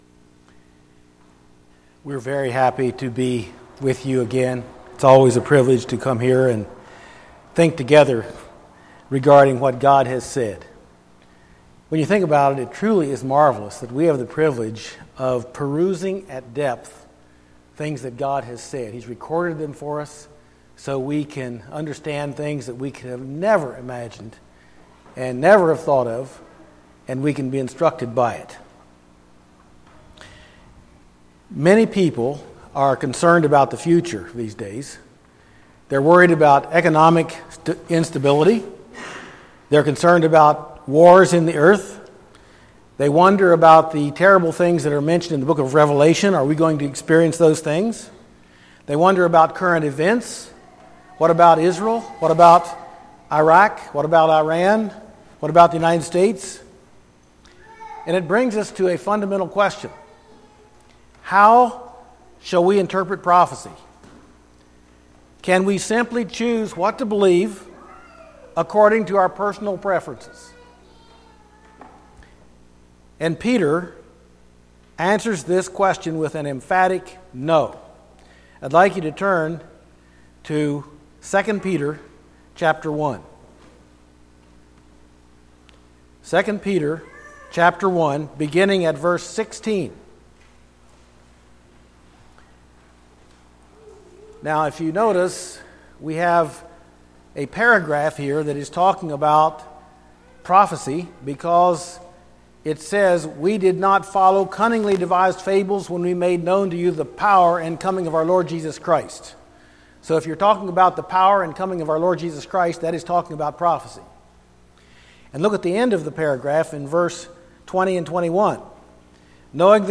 Bible Teaching Service Type: Thursday Evening %todo_render% « Lessons We Can Learn from the Pharisees and the Saducees Part 1 Luke